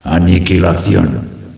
flak_m/sounds/announcer/est/rampage.ogg at 602a89cc682bb6abb8a4c4c5544b4943a46f4bd3
rampage.ogg